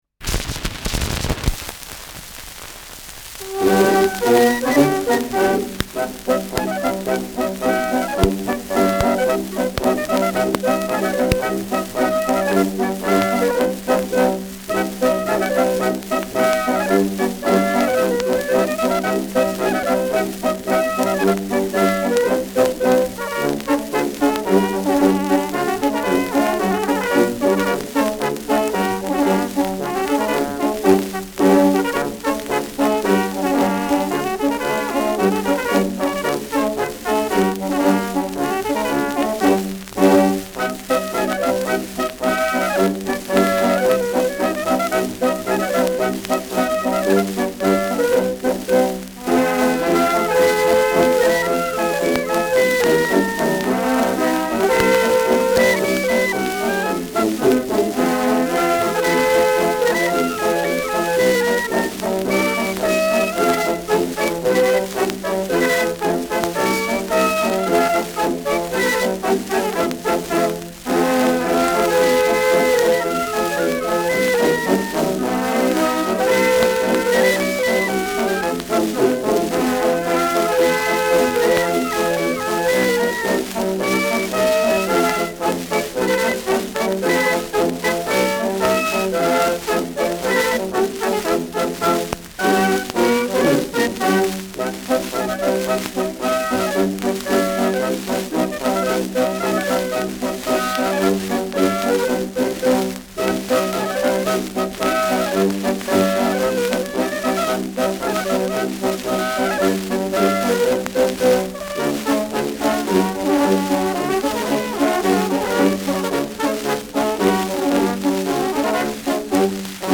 Schellackplatte
Abgespielt : Gelegentlich stärkeres Knacken : Erhöhtes Grundrauschen : Nadelgeräusch
Kapelle Greiß, Nürtingen (Interpretation)